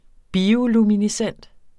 Udtale [ ˈbiːoluminiˌsεnˀd ]